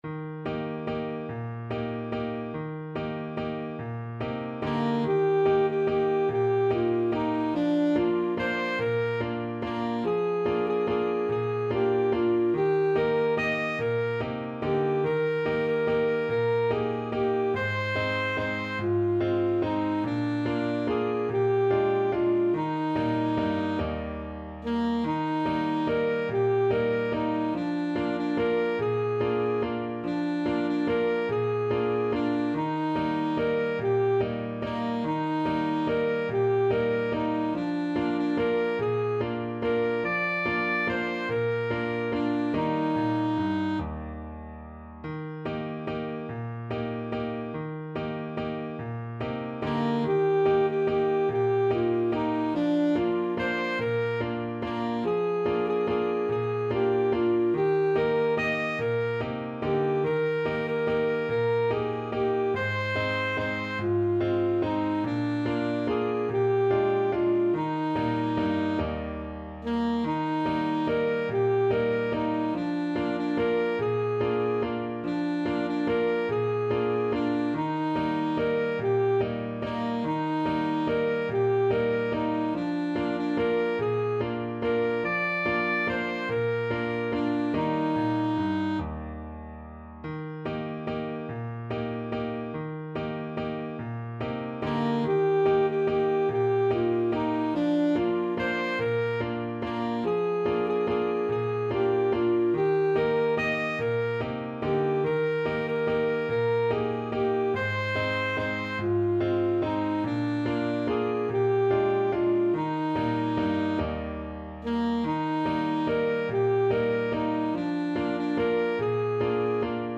Alto Saxophone
Steady one in a bar .=c.48
3/8 (View more 3/8 Music)
Traditional (View more Traditional Saxophone Music)
Swiss